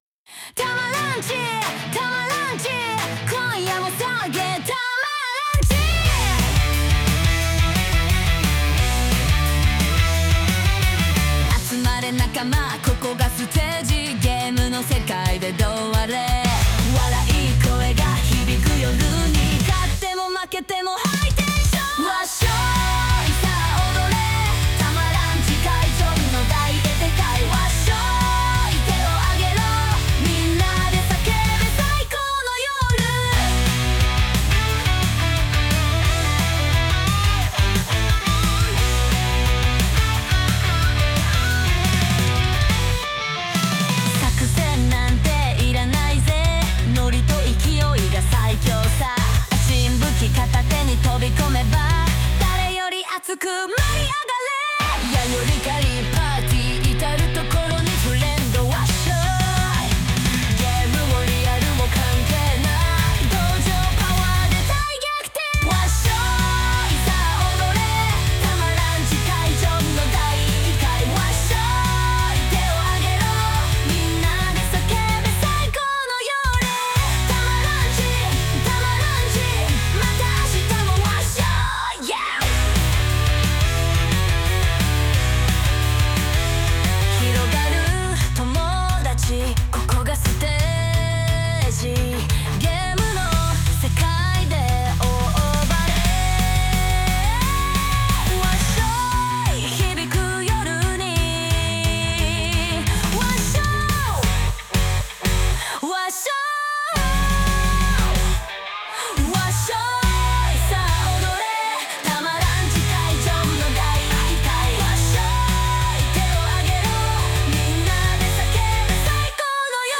デスボイスでシャウトするわっしょーいが印象的になりました。